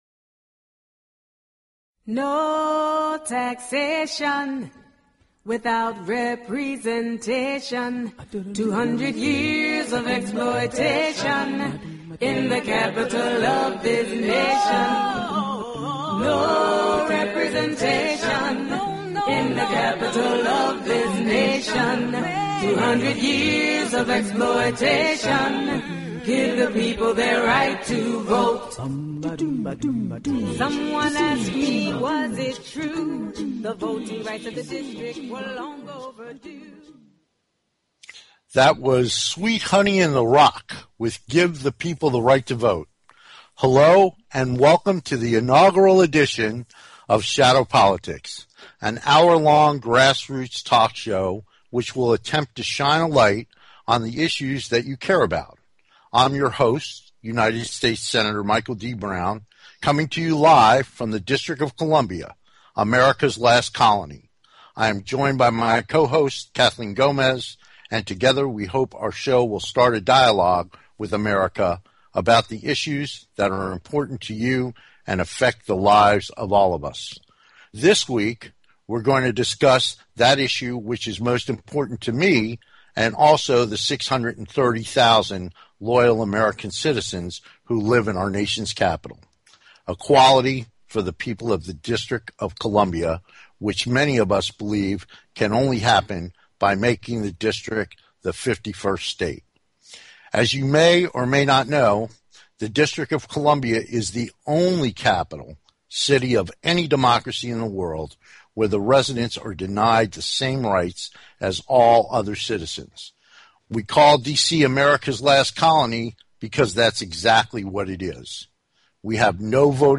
The show is not only informative but intertaining, and they both convey their opinions with humor, wit and a strong rapport.
Shadow Politics is a grass roots talk show giving a voice to the voiceless.